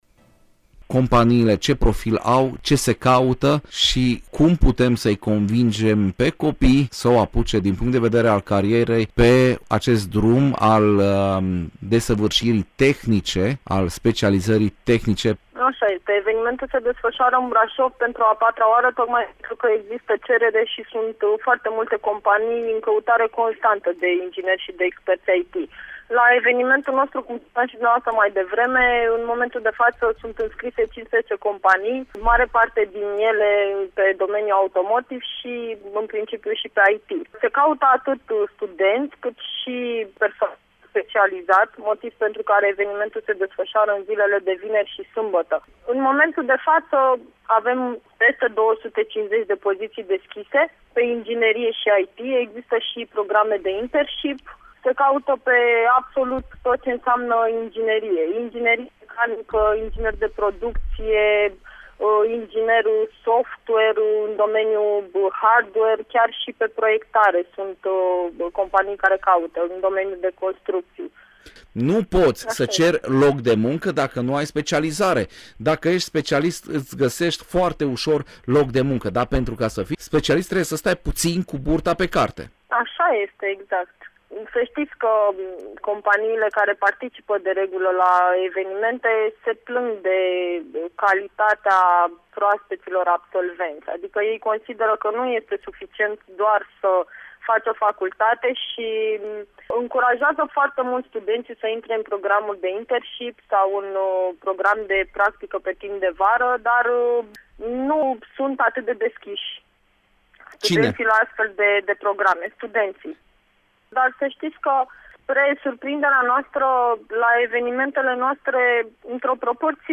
4-mai-Interviu-Ingineri.mp3